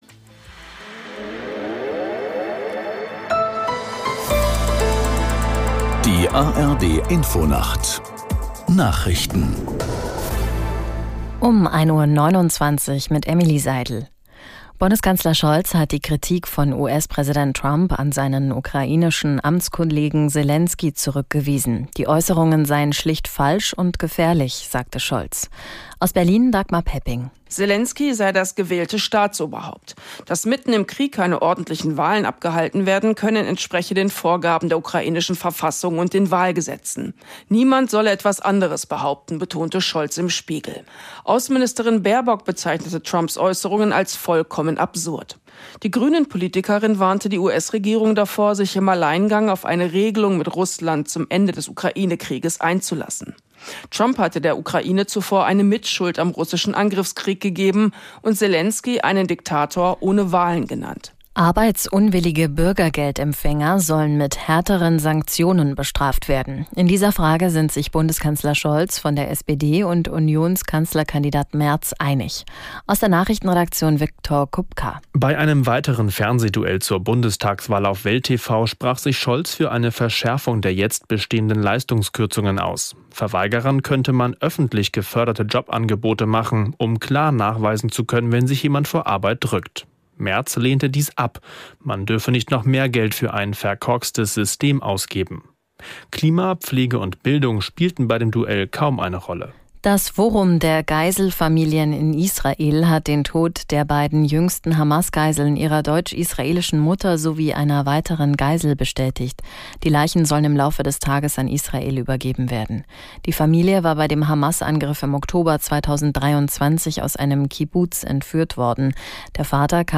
Nachrichten.